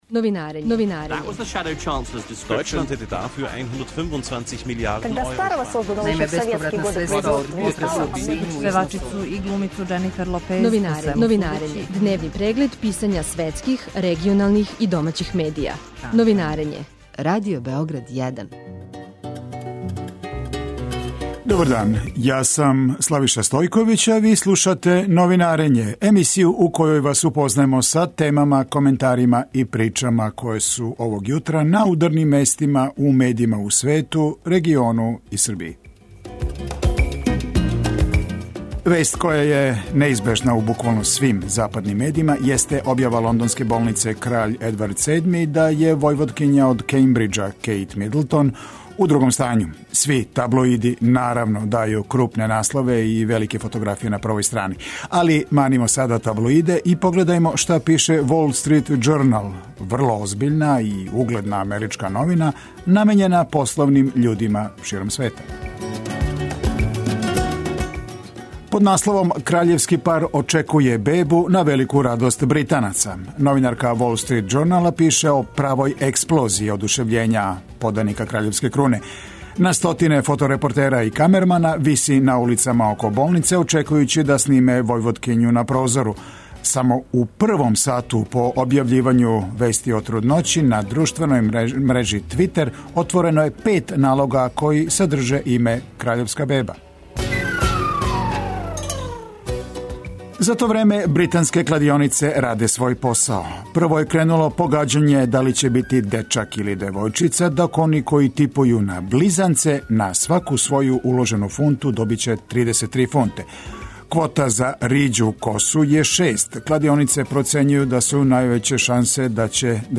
Теме за разговор су свакодневни улични протести у више словеначких градова, као и очекивања од новоизабраног председника Словеније Борута Пахора. Објављујемо и уобичајени преглед писања страних и домаћих медија.